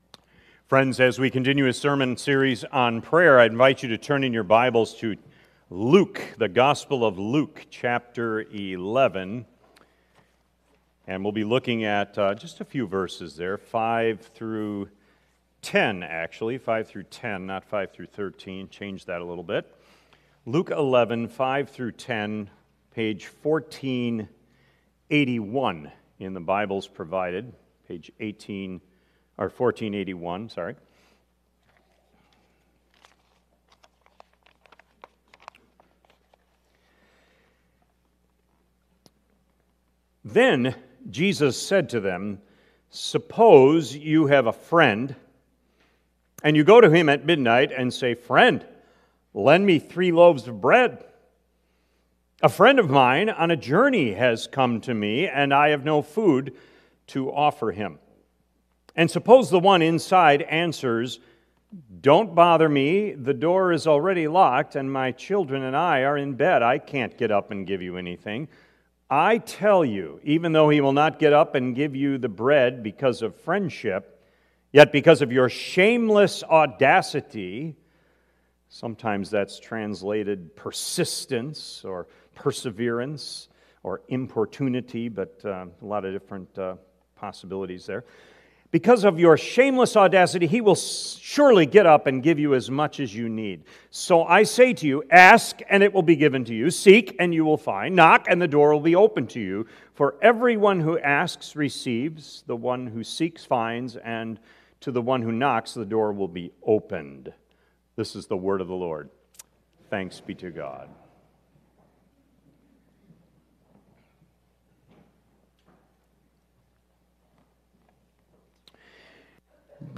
Sermon Recordings | Faith Community Christian Reformed Church